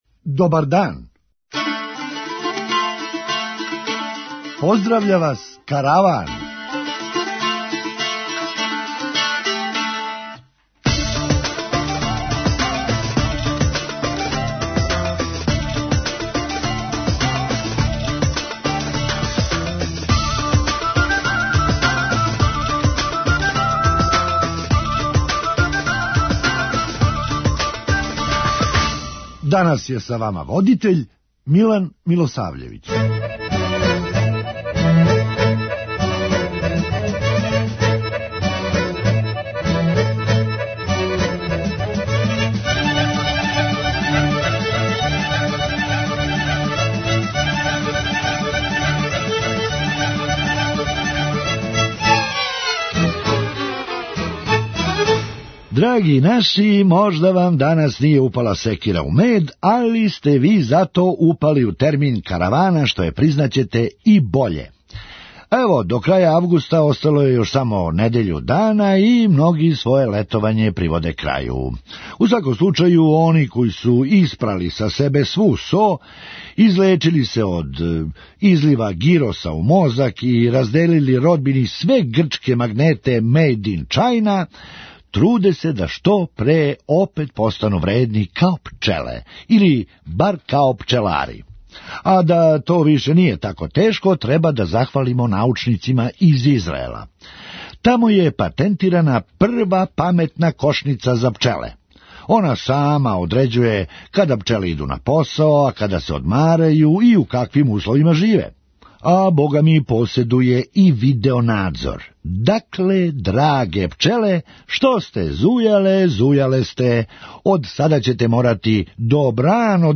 Хумористичка емисија
Да се појавила у Италији назвали би је „Крофна ностра“. преузми : 8.88 MB Караван Autor: Забавна редакција Радио Бeограда 1 Караван се креће ка својој дестинацији већ више од 50 година, увек добро натоварен актуелним хумором и изворним народним песмама.